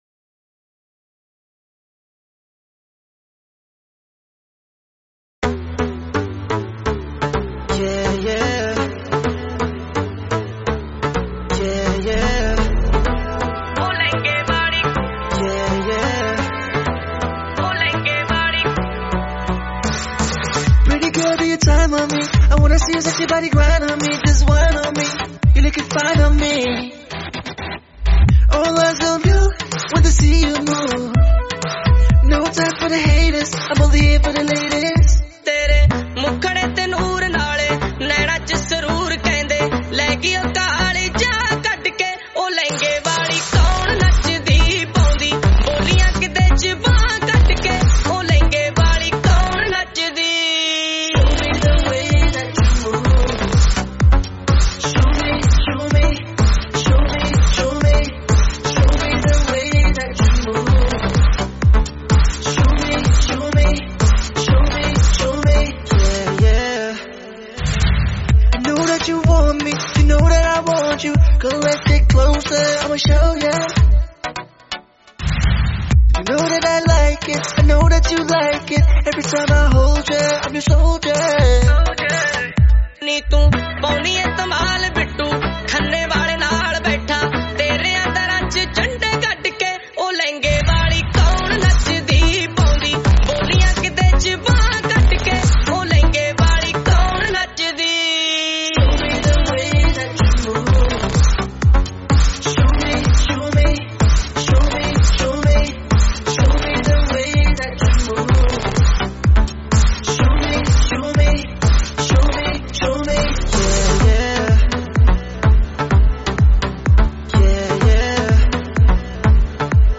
Latest Punjabi Videos